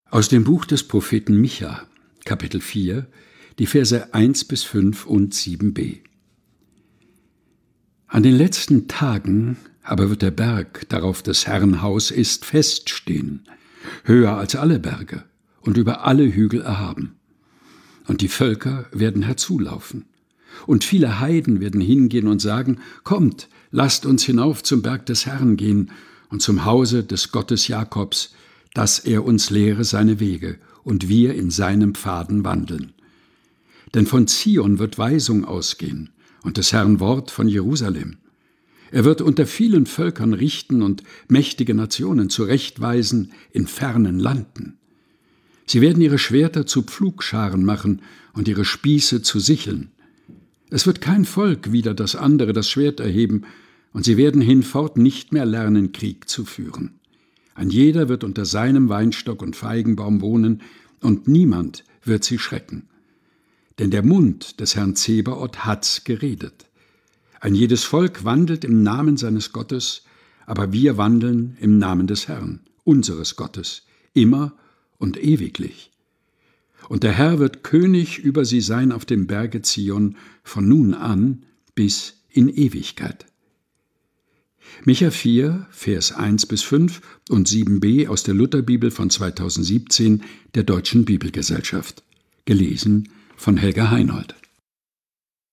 Predigttext zum drittletzten Sonntag de Kirchenjahres 2023/2024.